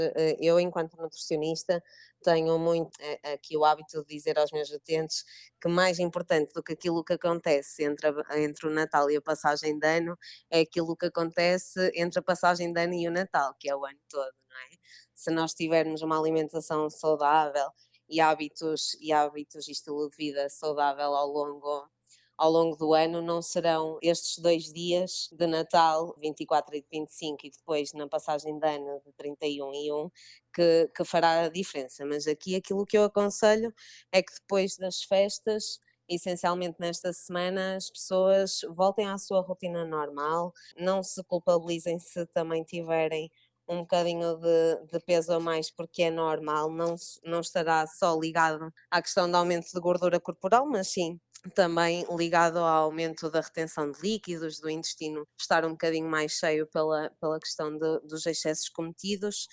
Fomos falar com uma nutricionista para perceber o que as pessoas podem fazem para voltar ao seu peso normal.
nutricionista